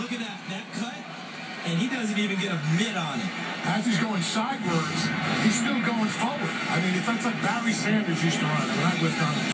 -Did you hear Siragusa use the word sidewards instead of sideways?